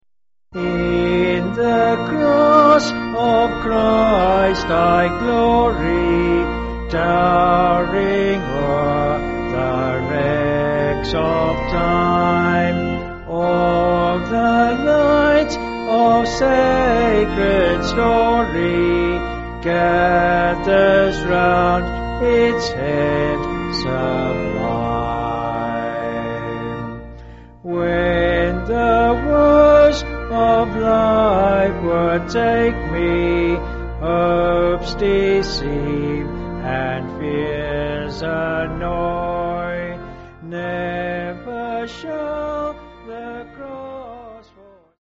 4/Bb
Vocals and Organ